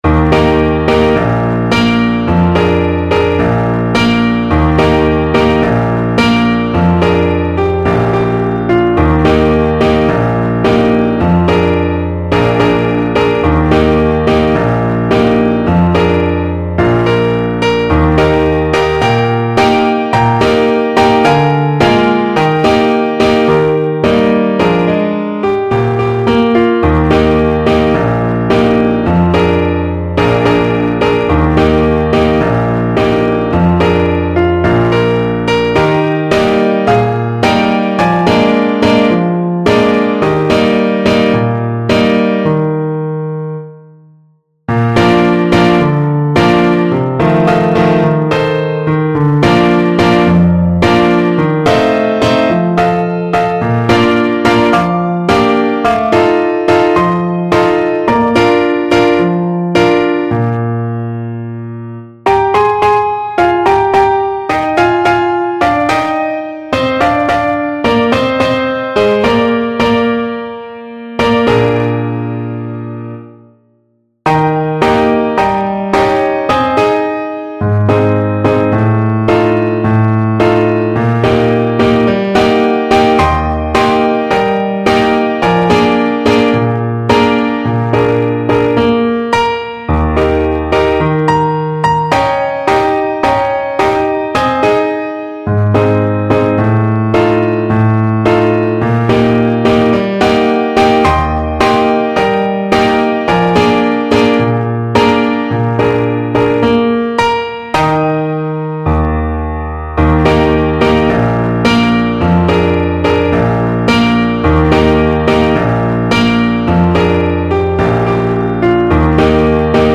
Play (or use space bar on your keyboard) Pause Music Playalong - Piano Accompaniment Playalong Band Accompaniment not yet available transpose reset tempo print settings full screen
Clarinet
Eb major (Sounding Pitch) F major (Clarinet in Bb) (View more Eb major Music for Clarinet )
2/4 (View more 2/4 Music)
World (View more World Clarinet Music)
Brazilian Choro for Clarinet